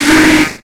Cri de Scorplane dans Pokémon X et Y.